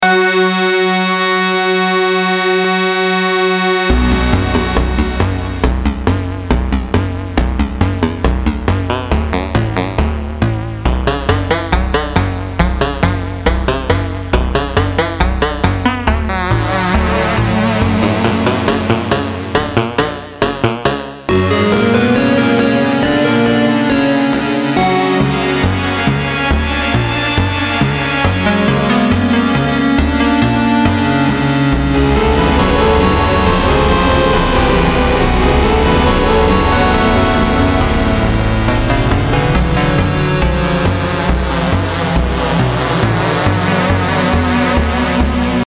Cadans S16 - это VST, AU плагин-ромплер от российского разработчика UltraRare, сделанный на основе звуков редкого советского синтезатора Cadans S12 с увеличенной полифонией, что принципиально расширяет возможности плагина сохраняя аутентичность оригинала.